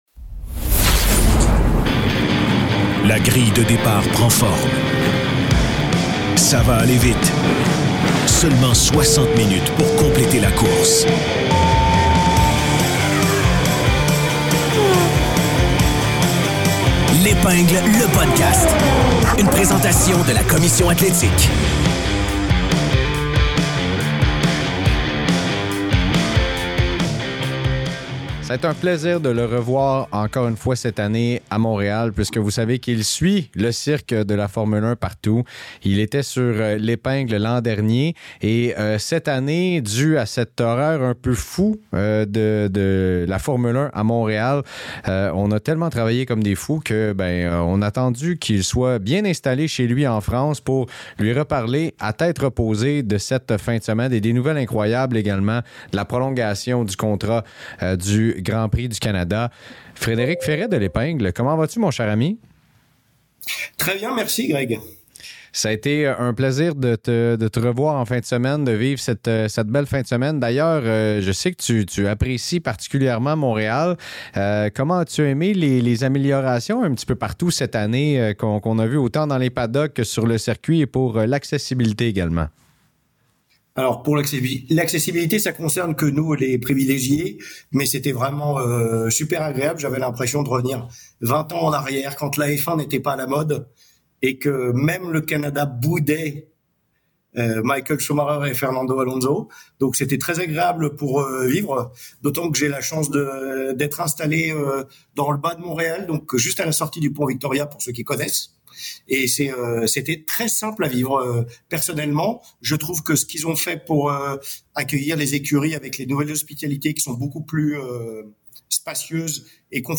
BPM Sports Entrevue